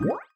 etfx_spawn.wav